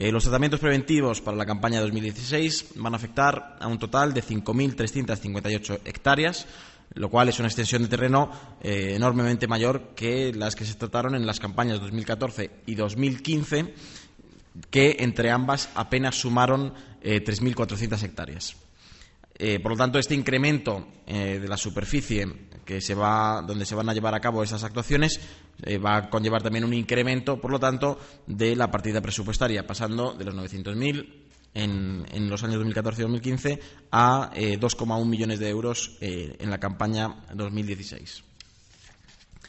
El portavoz del Gobierno regional, Nacho Hernando, ha explicado que